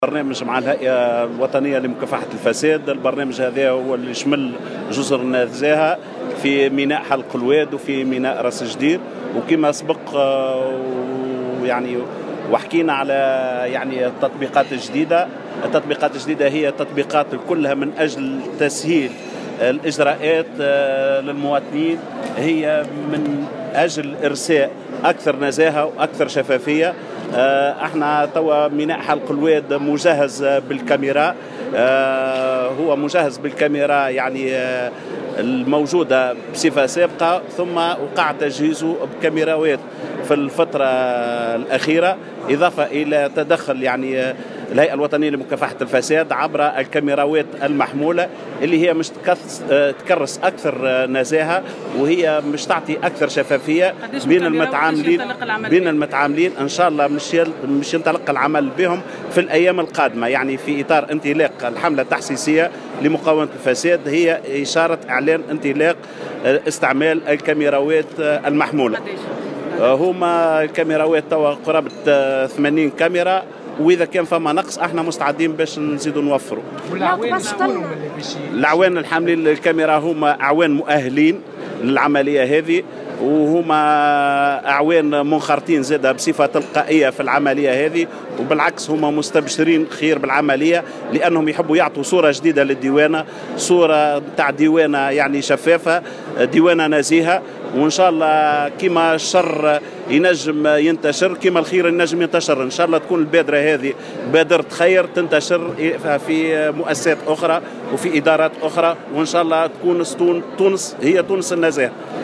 وأوضح في تصريح لـ "الجوهرة أف أم" ان سيتم اعتماد الكاميرات المحمولة بتدخل من الهيئة الوطنية لمكافحة الفساد.